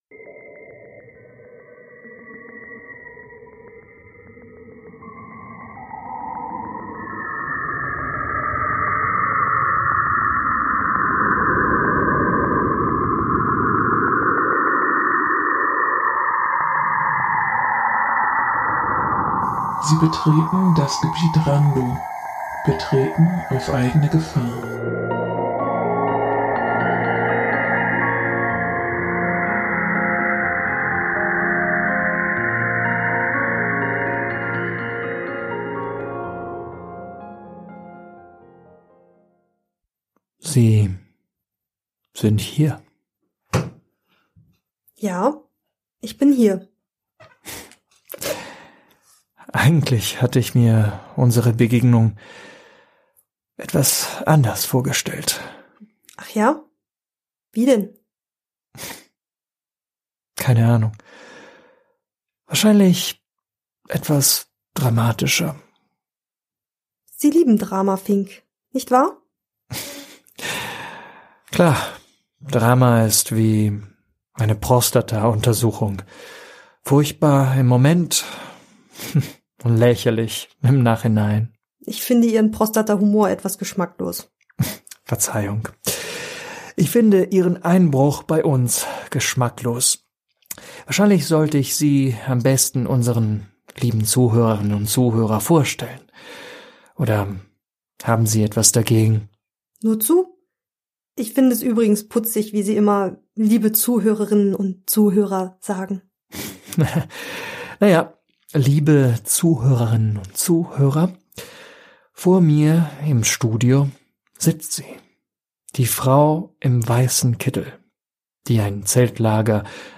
SFX: Cassini RPWS